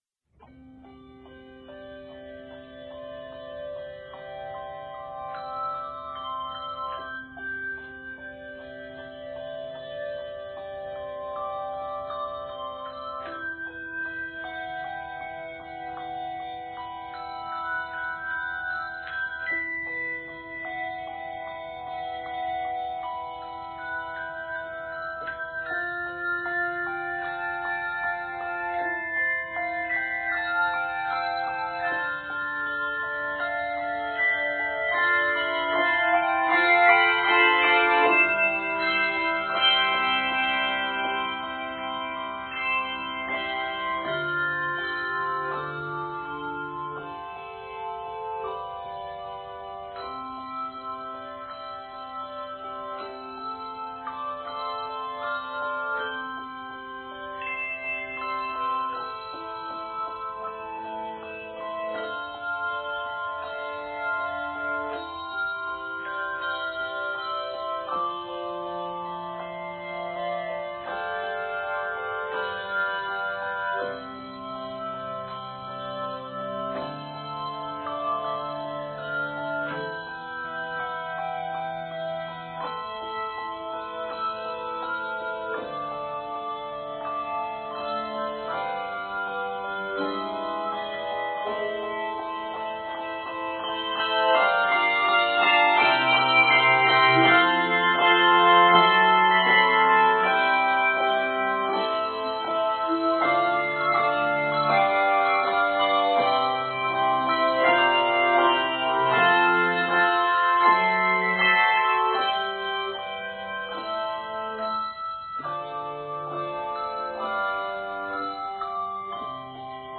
is a flowing, harmonically rich setting of the hymn